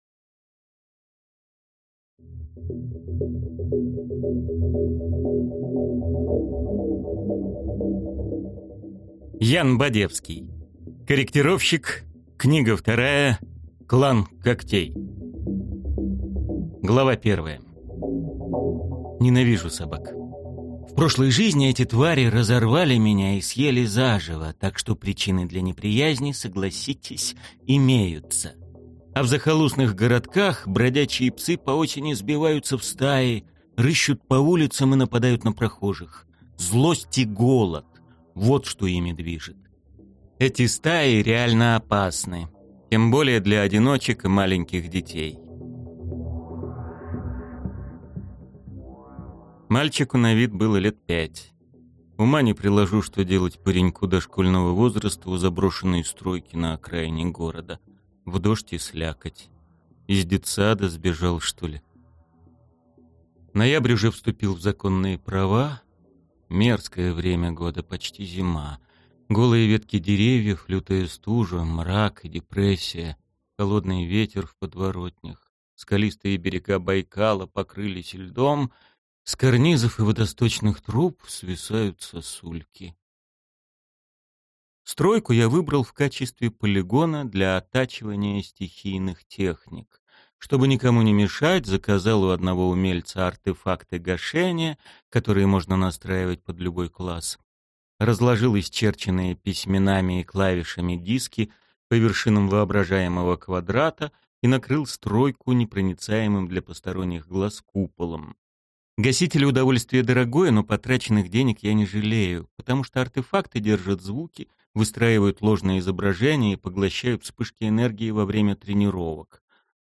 Аудиокнига Клан Когтей | Библиотека аудиокниг